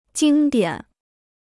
经典 (jīng diǎn): the classics; scriptures.
经典.mp3